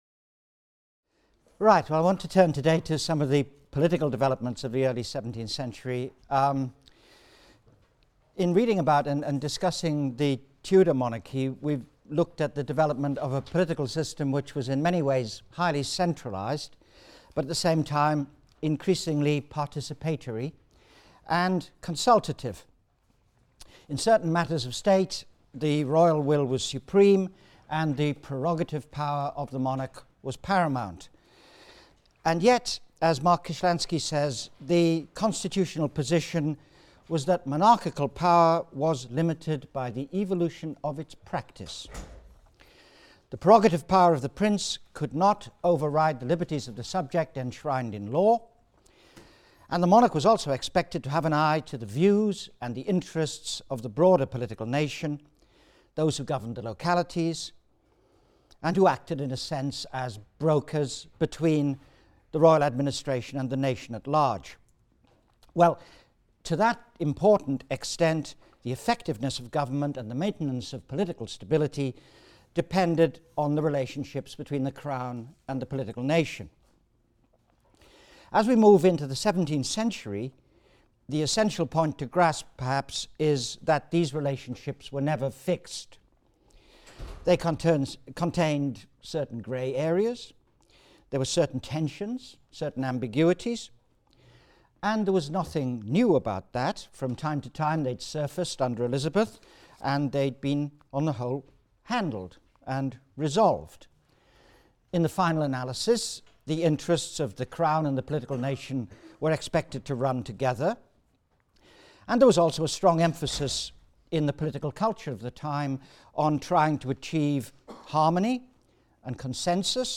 HIST 251 - Lecture 19 - Crown and Political Nation, 1604-1640 | Open Yale Courses